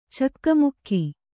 ♪ cakkamukki